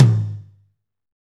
TOM M R H0BR.wav